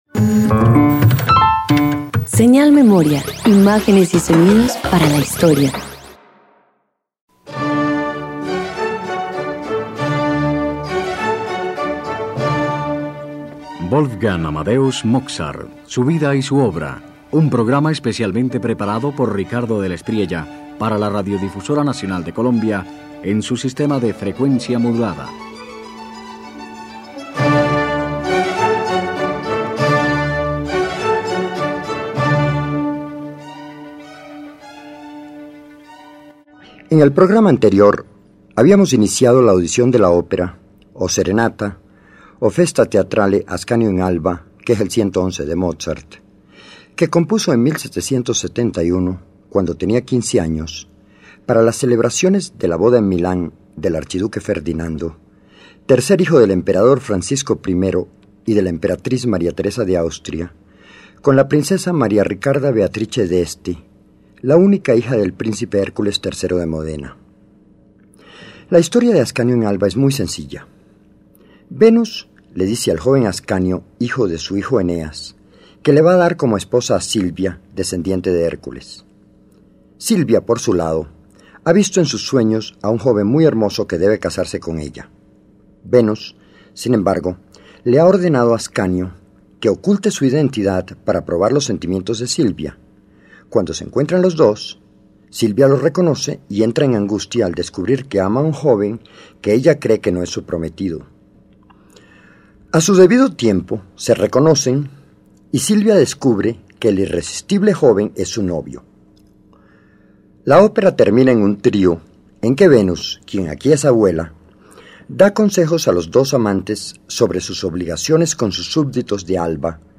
El escenario se llena de pastores y coros que llaman al amor. En su segunda parte, “Ascanio in Alba” se vuelve pura luz: música que sonríe, que no pretende dolor alguno. Mozart retrata la inocencia bucólica con la precisión de un arquitecto.